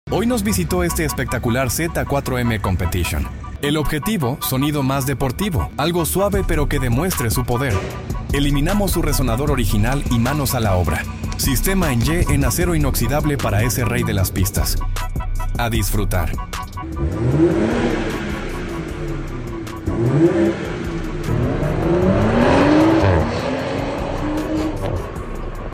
Eliminamos el resonador original y fabricamos un sistema en Y en acero inoxidable: ✅ Sonido más deportivo y agresivo